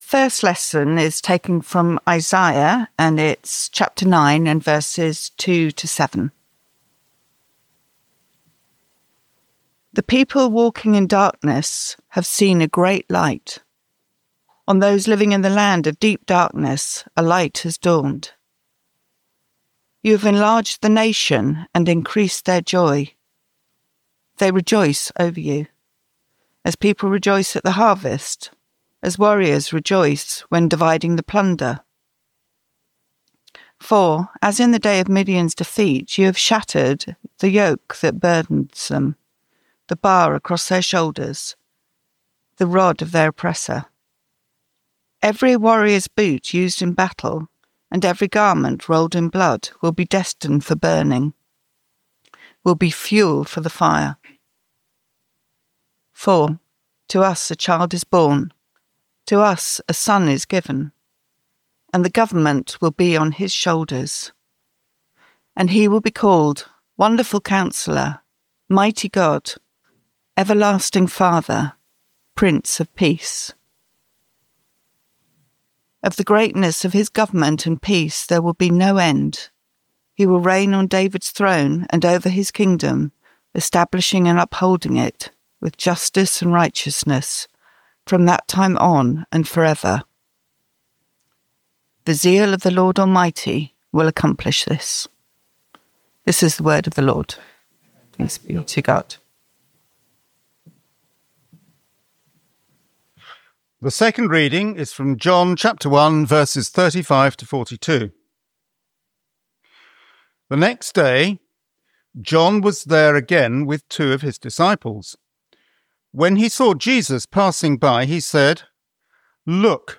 An introduction to this year’s Advent sermon series recorded at our special United Parish service at Seaforth Hall on Sunday 30th November.
Passage: Isaiah 9:2-7, John 1:35-42 Service Type: United Parish Service An introduction to this year’s Advent sermon series recorded at our special United Parish service at Seaforth Hall on Sunday 30th November.